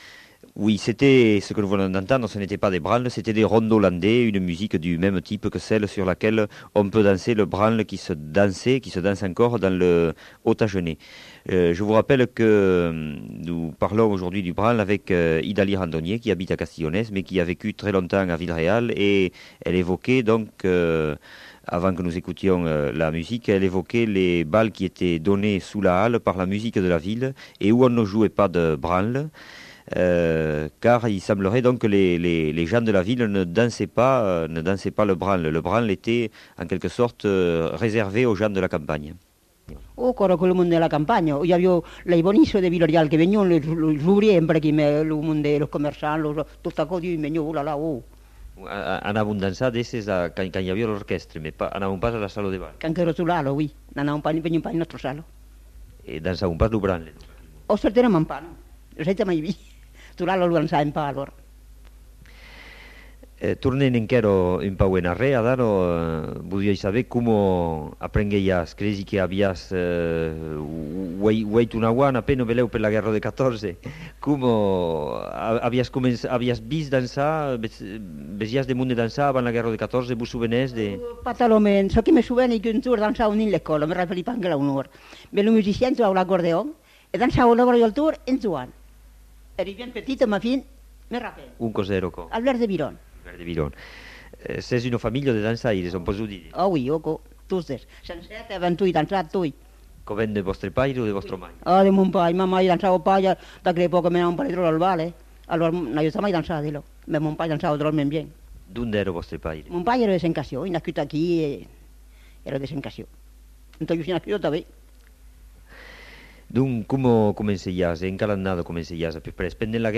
Lieu : Villeréal
Genre : témoignage thématique